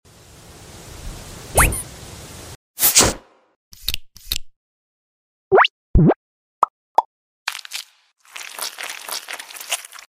Relaxing squishy hedgehog ASMR 🦔🫠